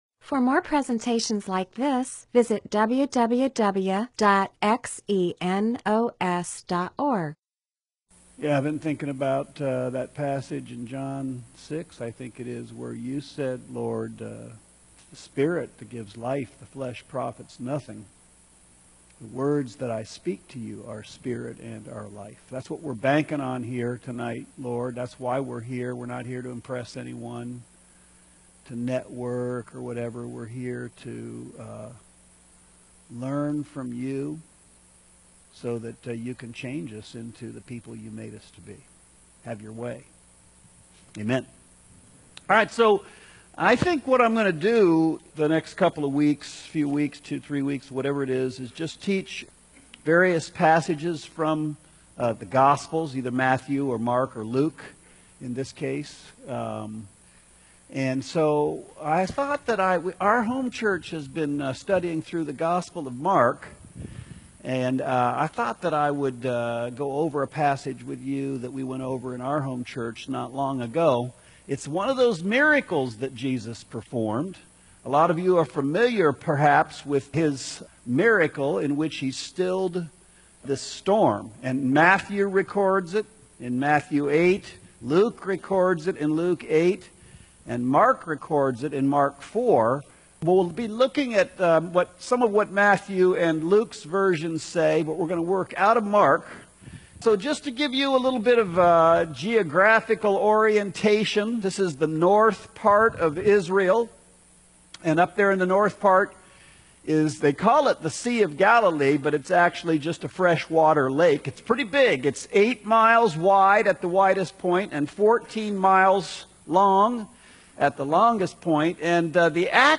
MP4/M4A audio recording of a Bible teaching/sermon/presentation about Mark 4:35-41.